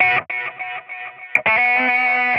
handling raw chicken1
描述：Separating a whole chicken
标签： sloshing chicken raw juicy meat squishing
声道立体声